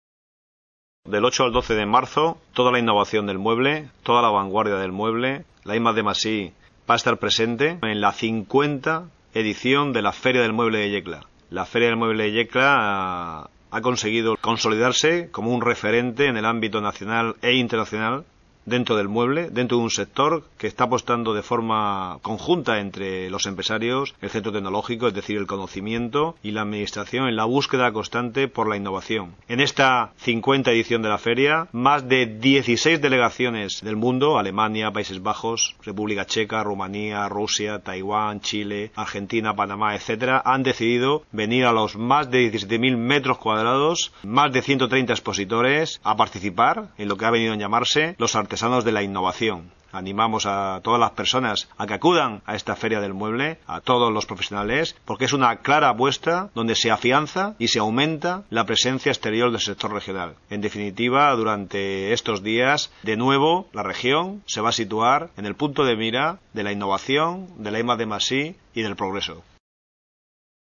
Declaraciones del consejero de Universidades, Empresa e Investigación, Salvador Marín, sobre la 50 edición de la Feria del Mueble de Yecla